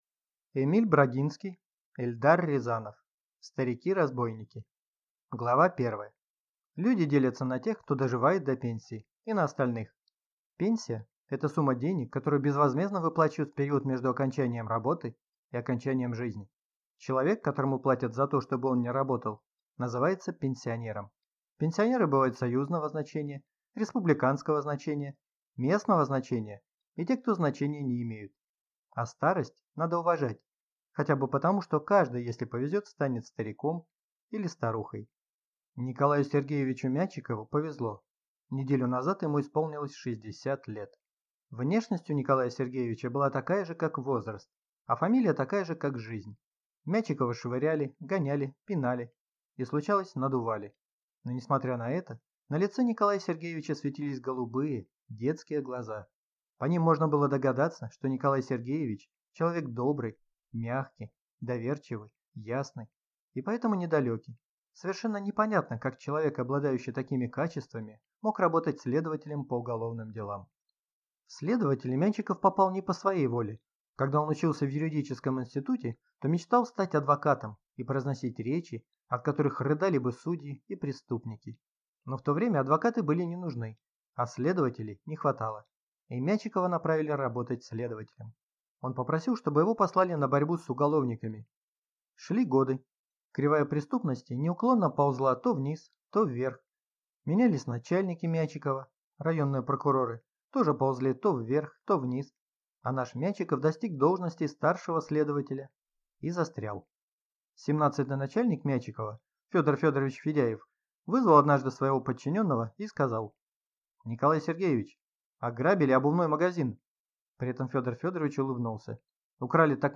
Аудиокнига Старики-разбойники | Библиотека аудиокниг